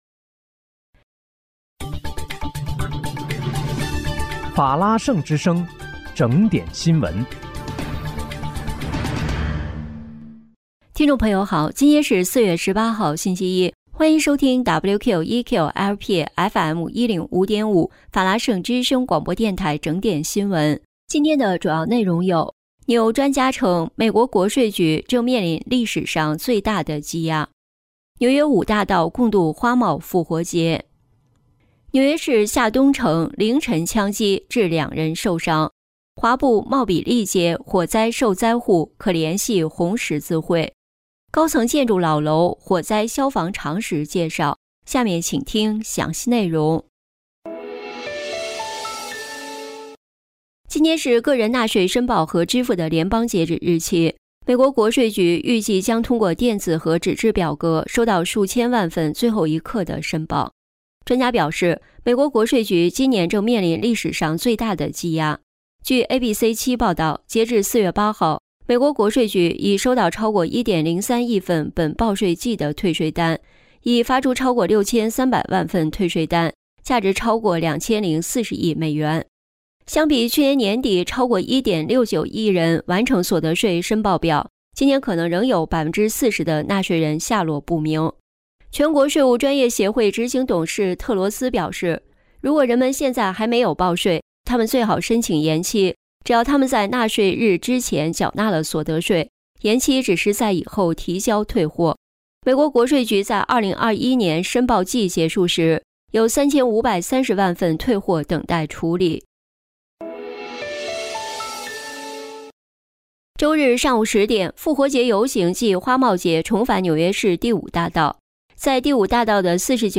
4月18日（星期一）纽约整点新闻